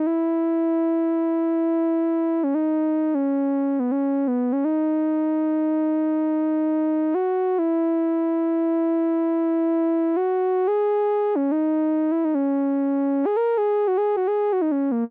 14 lead C1.wav